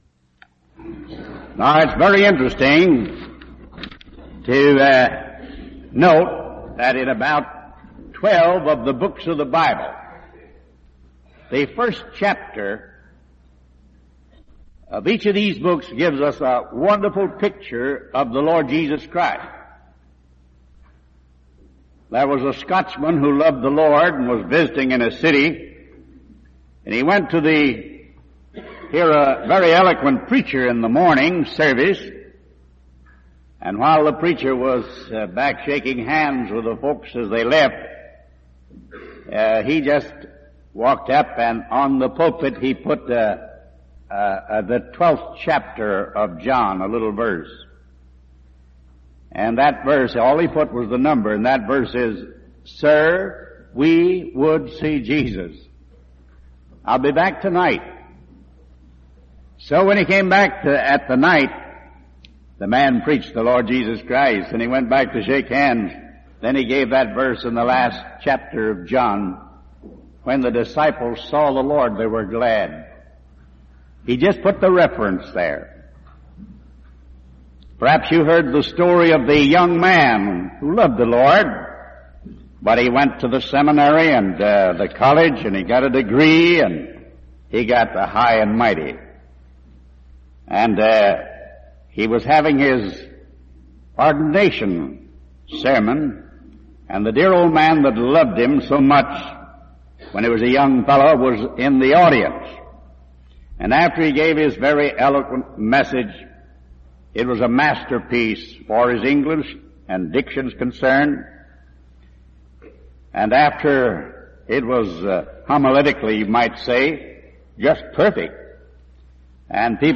When you're done, explore more sermons .